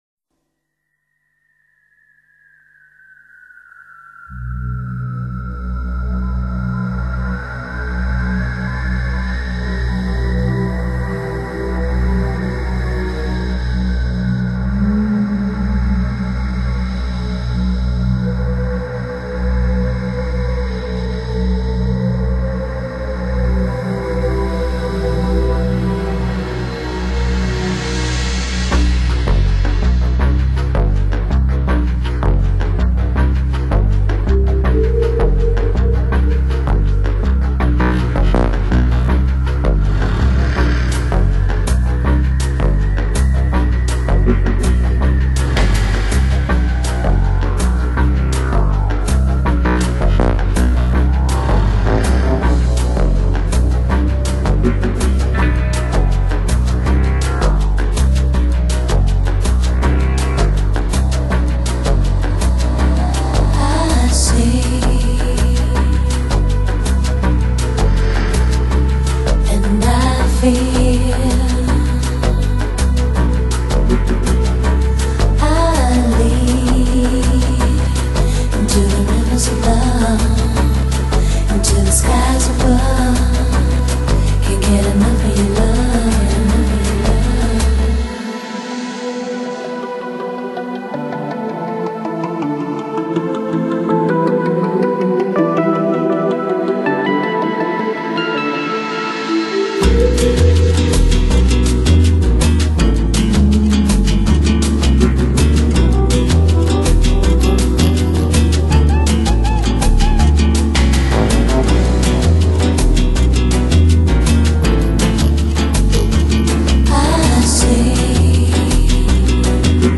2002-2011 Genre: Lounge | Lo-Fi | Chillout Quality